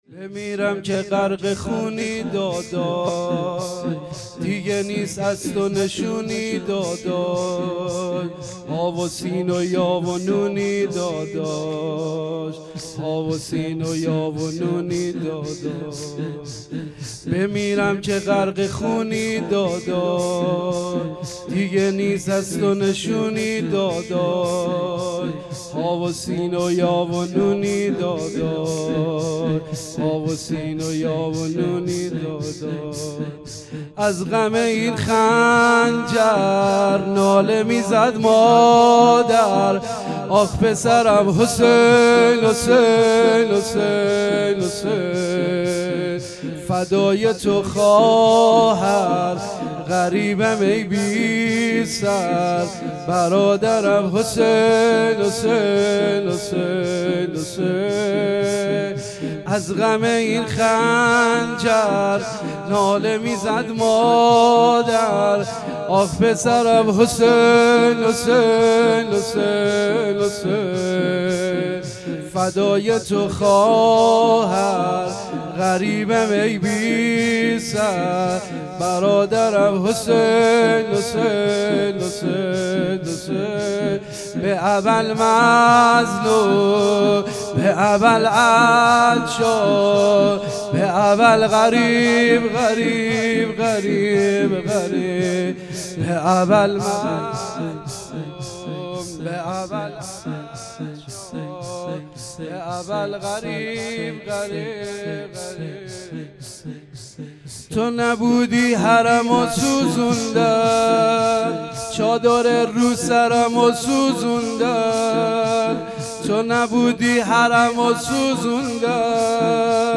شام غریبان محرم99